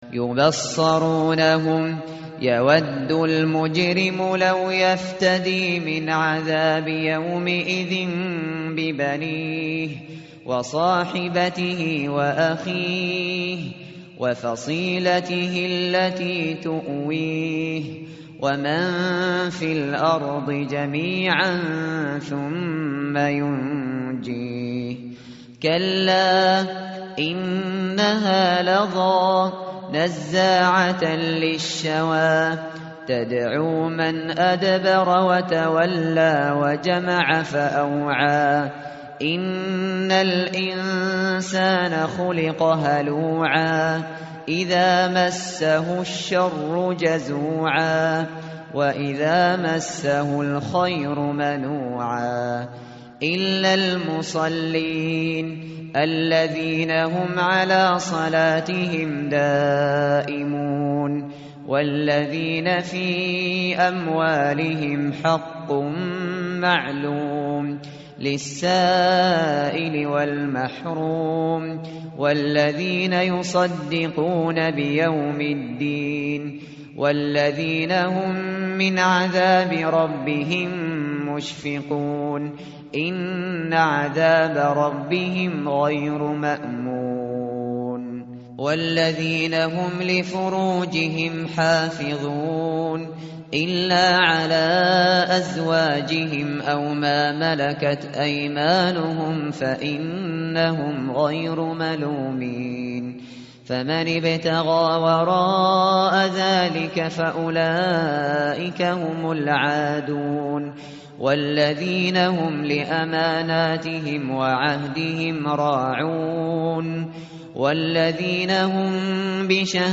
متن قرآن همراه باتلاوت قرآن و ترجمه
tartil_shateri_page_569.mp3